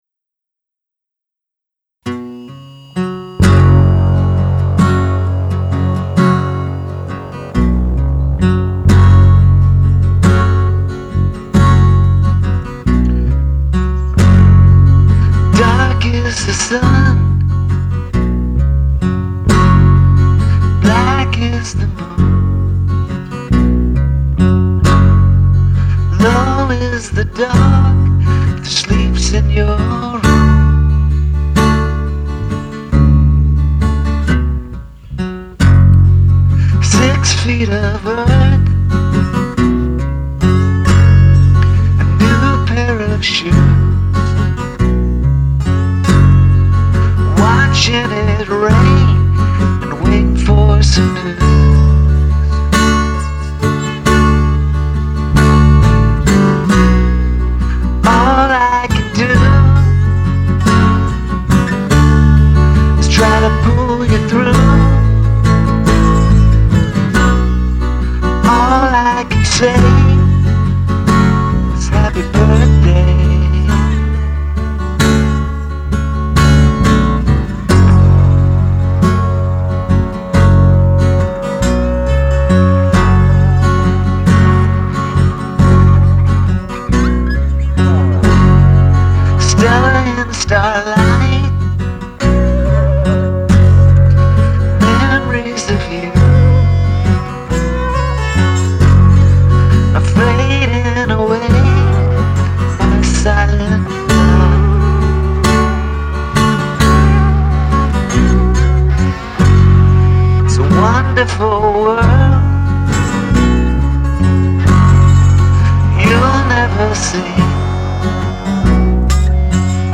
I recorded this at home on an 8-track cassette.
a stark, somber number